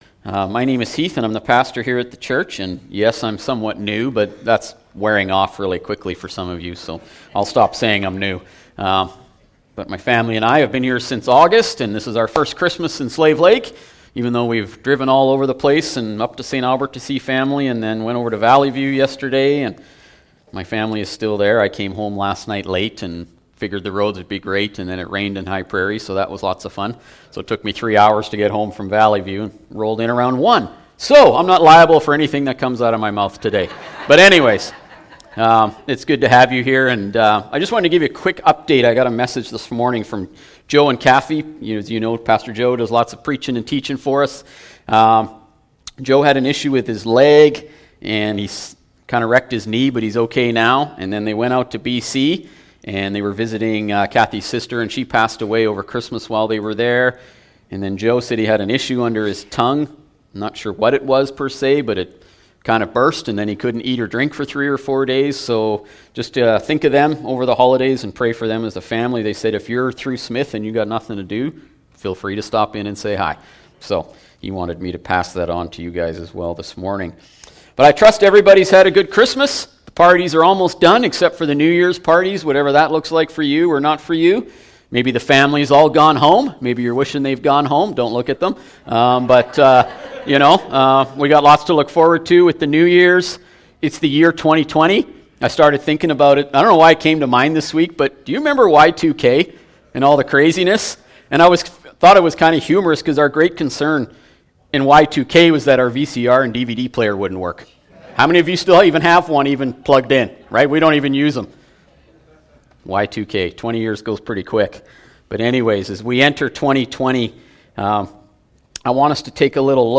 Sermons | Slave Lake Alliance Church